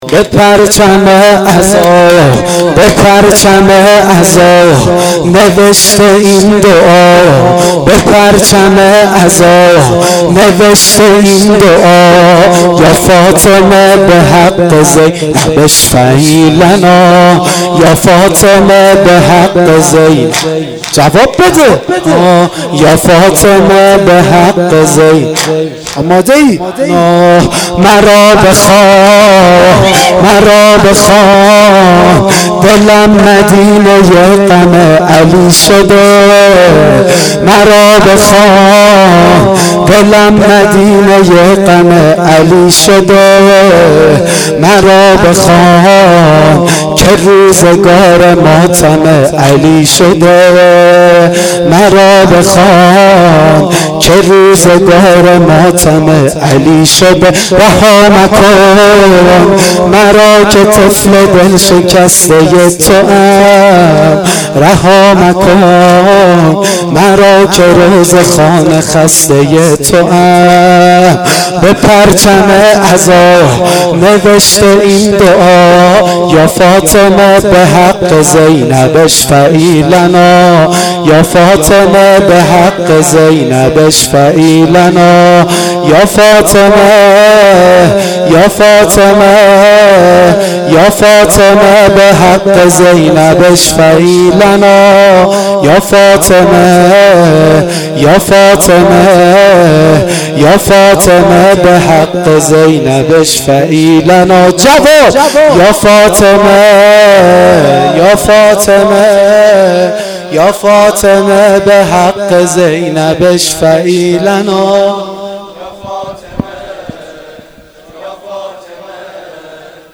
واحد شب دوم فاطمیه دوم